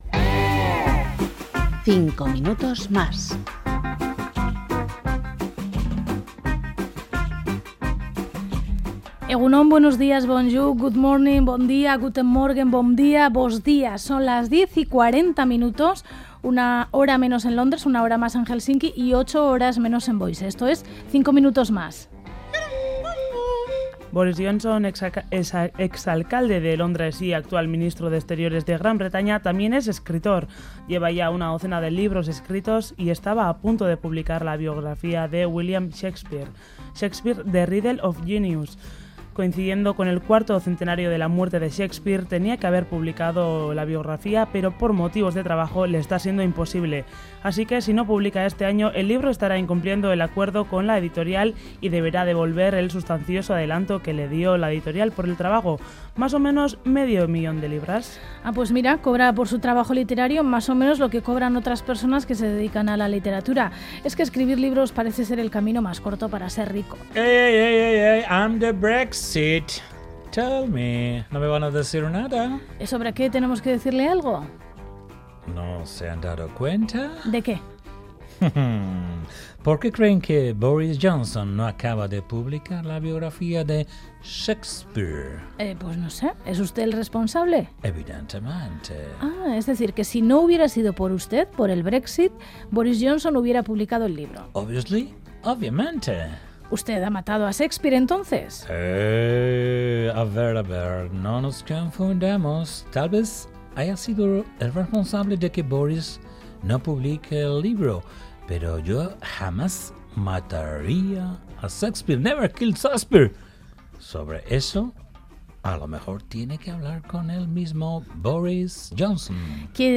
Audio: 5 MINUTOS MÁS es un informativo diferente que cuenta algunas de esas noticias que no son habituales en los informativos tradicionales.